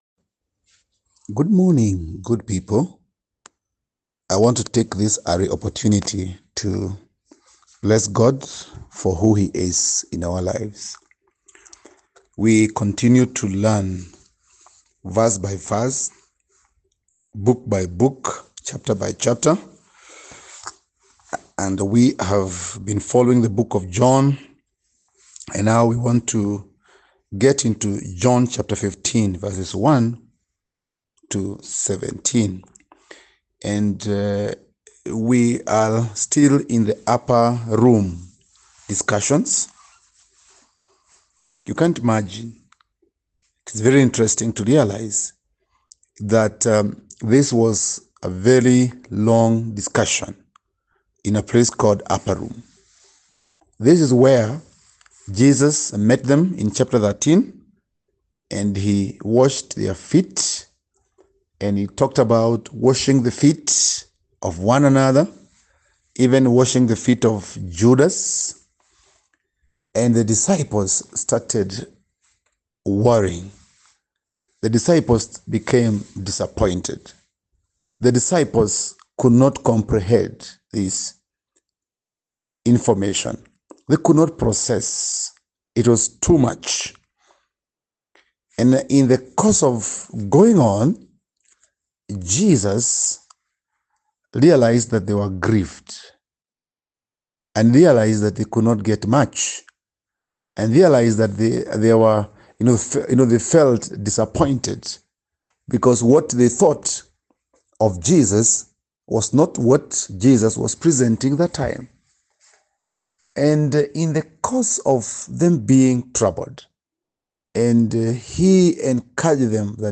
Post Lesson Teaching Summary Great job completing the study! Take a moment to listen to this summary to reinforce your group’s understanding of the text and ensure you’re all on the same page.